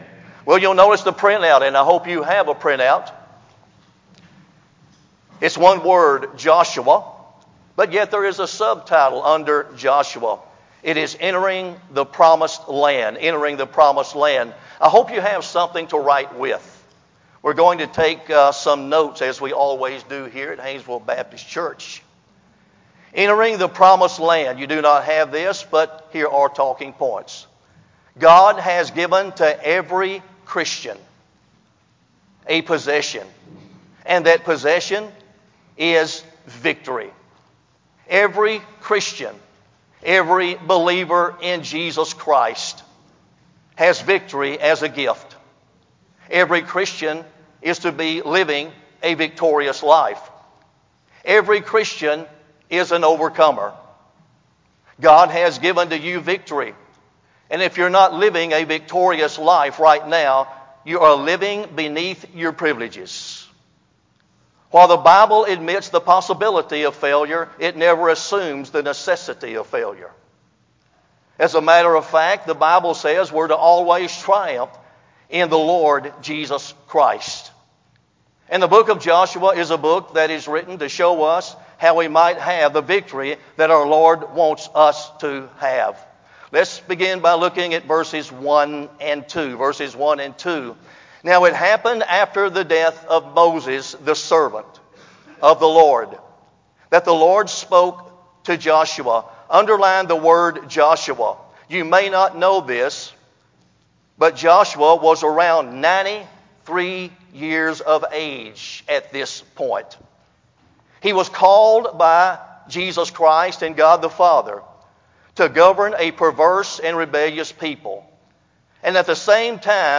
125th Homecoming Sermon on Joshua 1